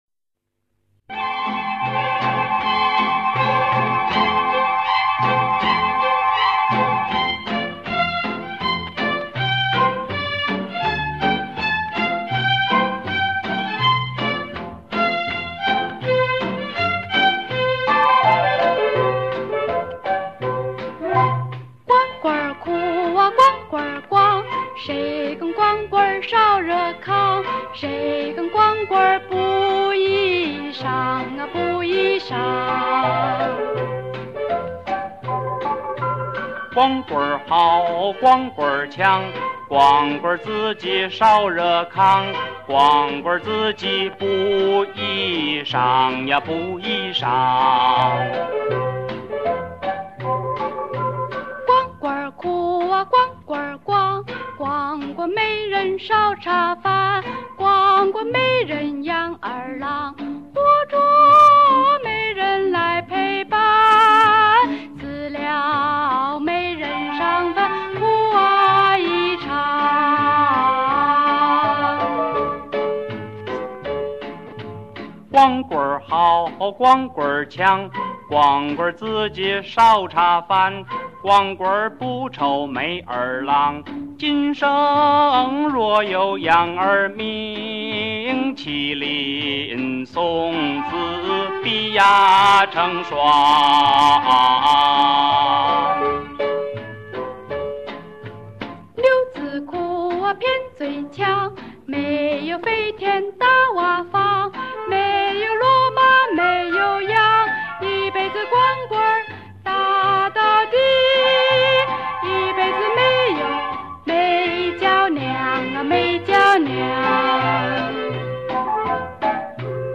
就是音质差了点，128k的，先凑合听